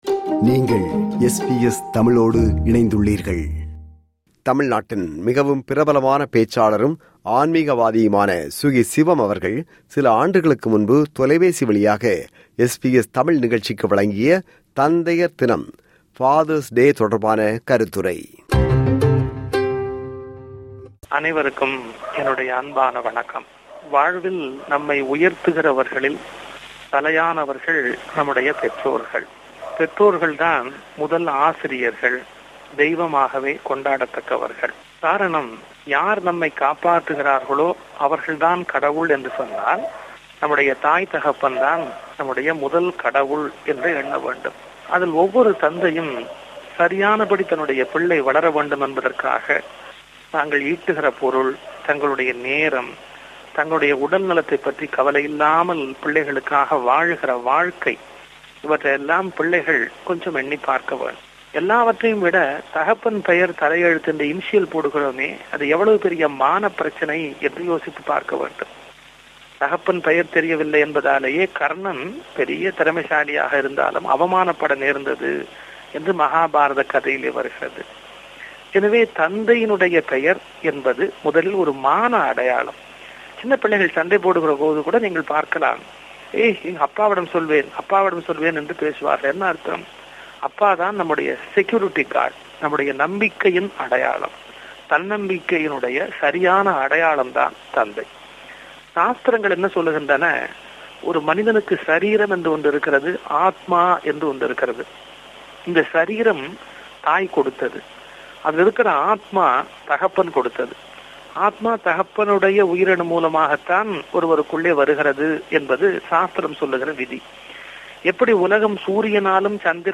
தமிழ்நாட்டின் மிகவும் பிரபலமான பேச்சாளரும், ஆன்மீகவாதியுமான சுகி.சிவம் அவர்கள் சில ஆண்டுகளுக்கு முன்பு தொலைபேசி வழியாக SBS தமிழ் நிகழ்ச்சிக்கு வழங்கிய “தந்தையர் தினம்” தொடர்பான கருத்துரை.